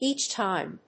アクセントéach tíme